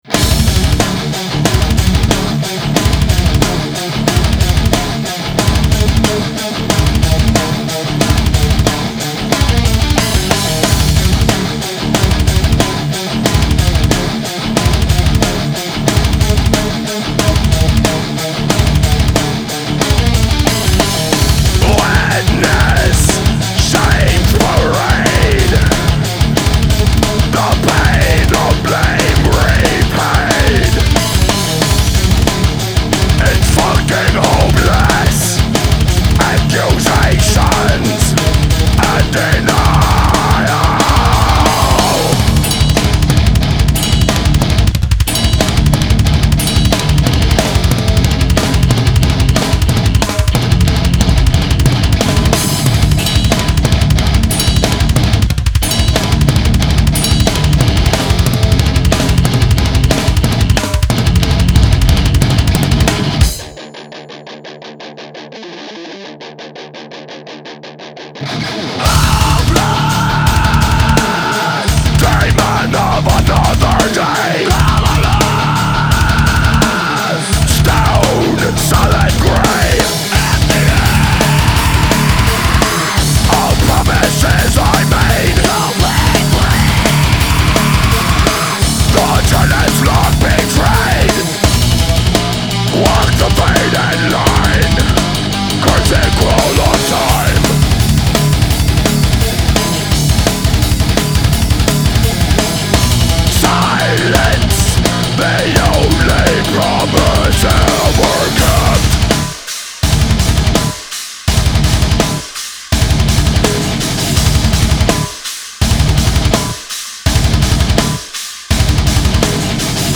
Groove Metal